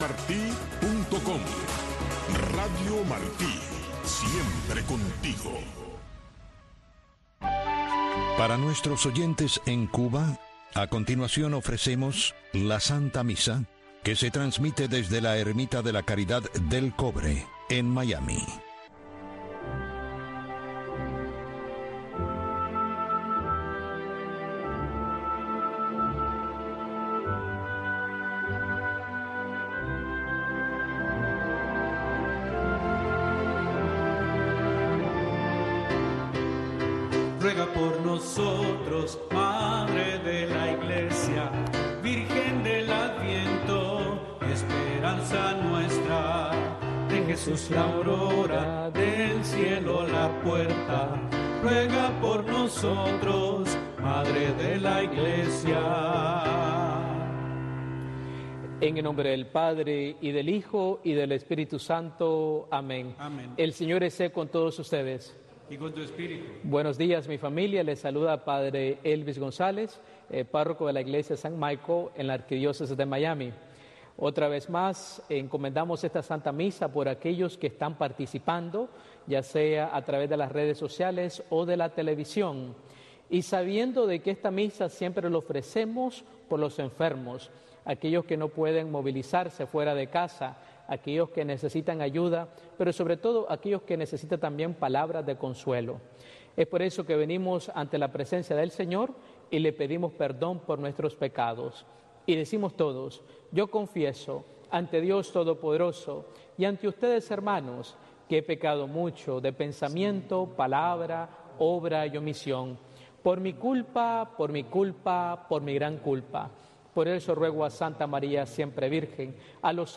La Santa Misa
El Santuario Nacional de Nuestra Señor de la Caridad, más conocido como la Ermita de la Caridad, es un templo católico de la Arquidiócesis de Miami dedicado a Nuestra Señora de la Caridad, Patrona de Cuba.